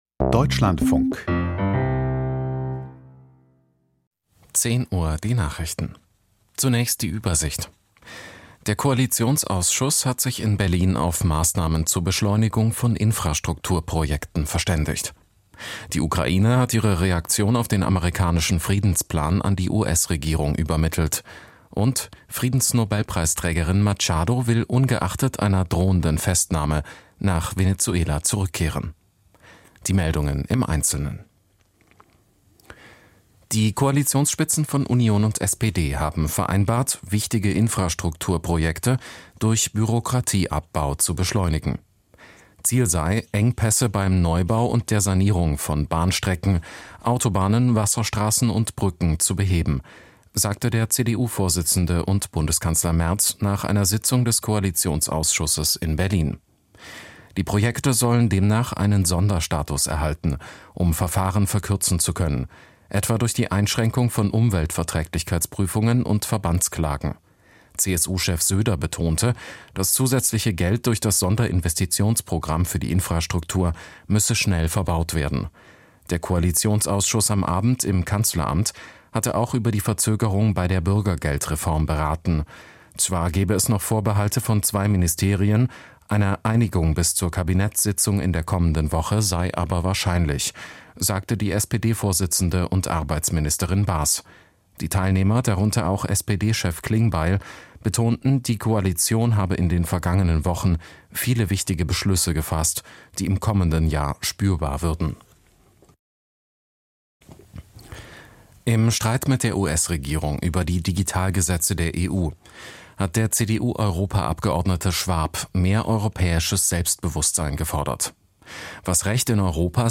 Aus der Deutschlandfunk-Nachrichtenredaktion.